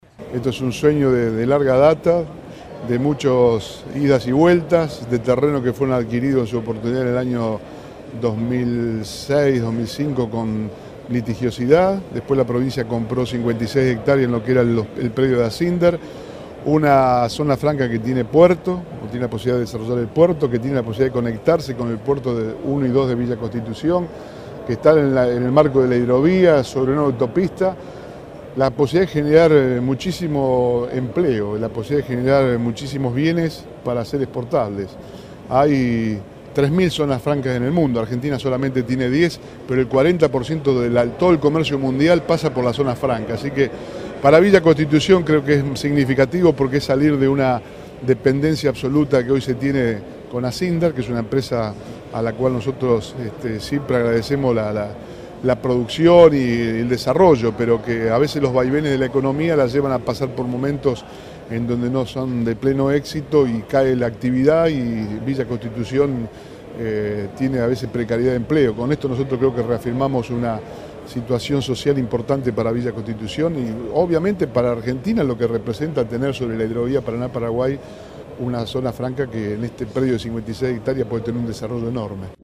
Declaraciones de Antonio Bonfatti.